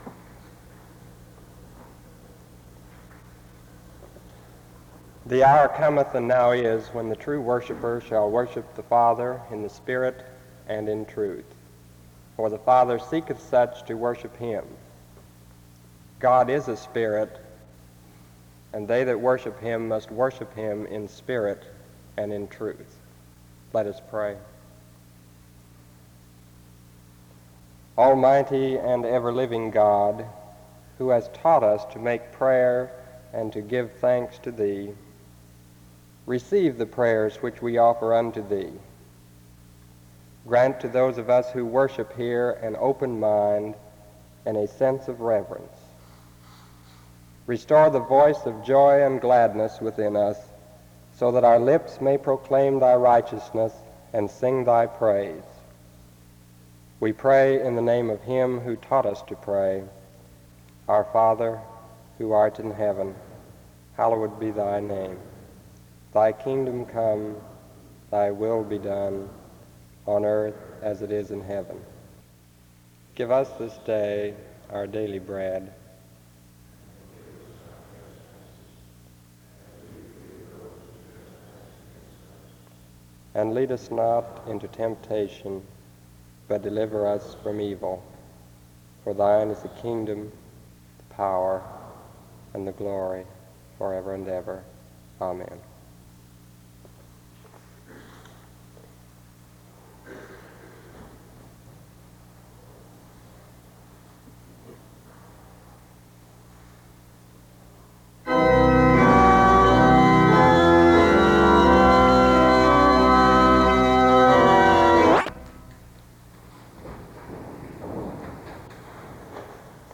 Download .mp3 Description The service begins with the reading of John 4:23-24 and prayer (00:00-01:35), followed by a time of confessional prayer, (02:34-03:47) prayers of thanksgiving, (03:48-05:33) and prayers for the seminary community (05:34-07:22). Next follows a responsive reading of Selection 30 entitled “God our Security” (07:23-09:21) and congregational worship (09:22-13:02).